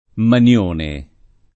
[ man L1 ne ]